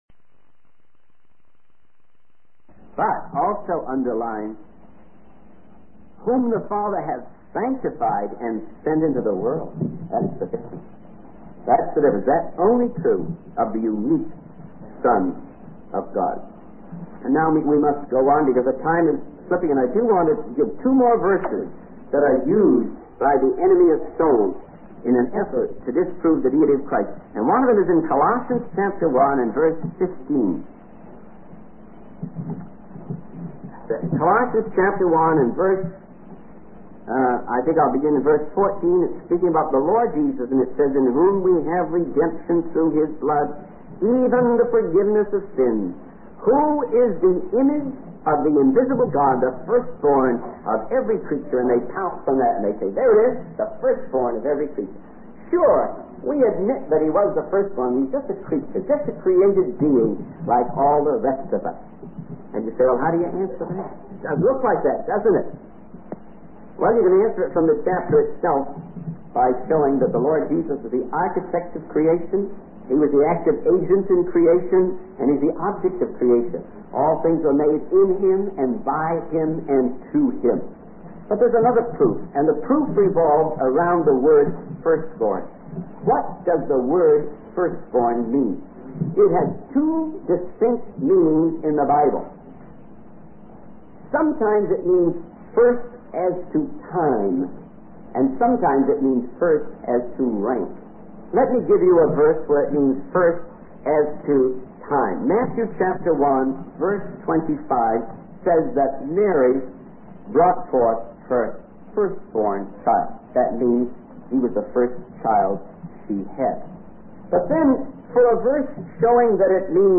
In this sermon, the speaker emphasizes the importance of going back to the simplicity of the message of the Gospel while being open to changing methods. He uses the example of cereal companies improving their sales by improving the packaging rather than the product itself. The speaker also highlights the significance of the church in the New Testament and encourages listeners to prioritize the assembly in their lives.